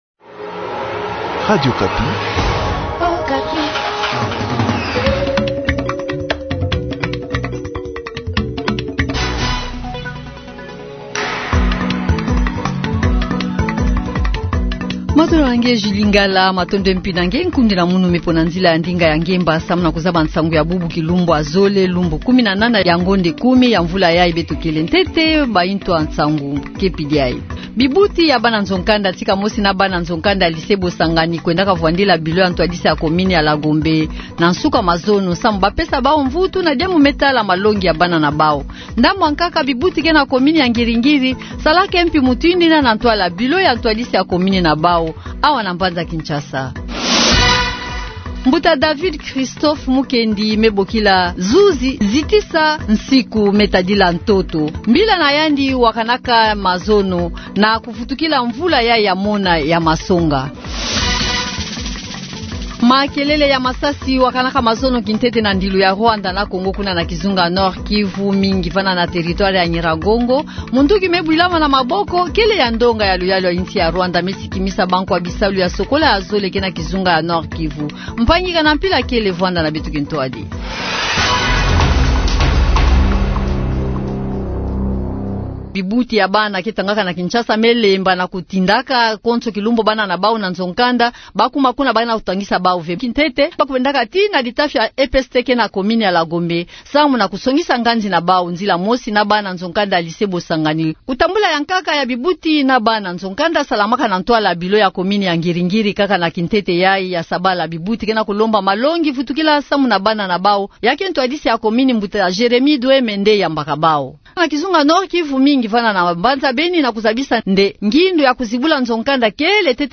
journal Kikongo de ce matin